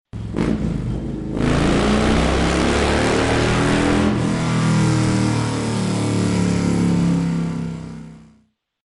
Dzwonki Motocykl
Kategorie Efekty Dźwiękowe